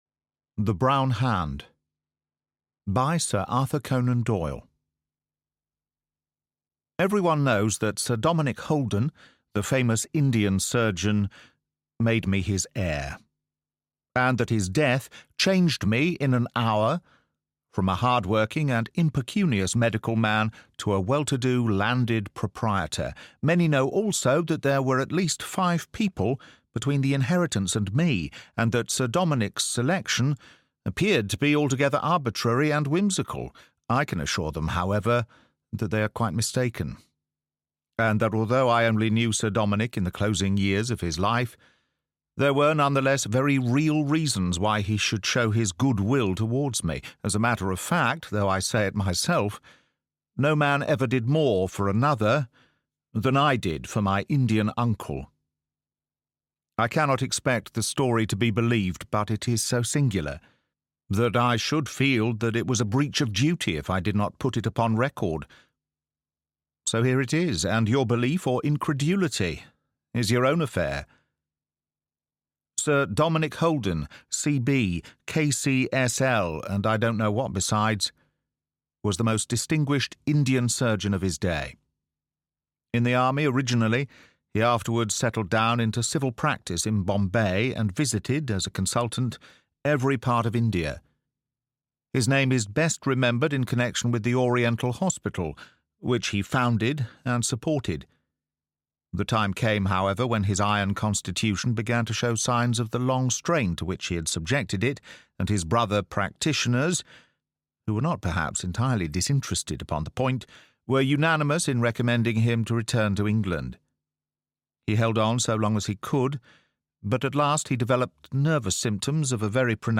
The Silver Hatchet: Adventure and Suspense (Audiobook)